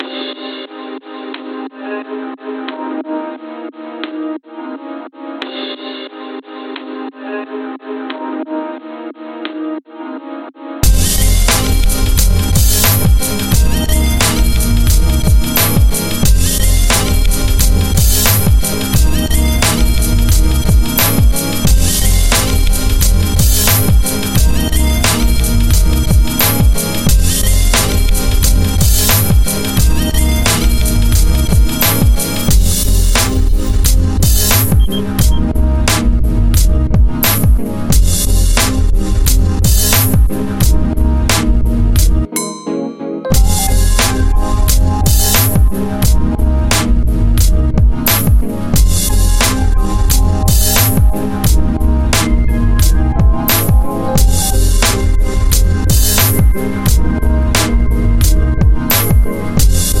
Hip Hop Sale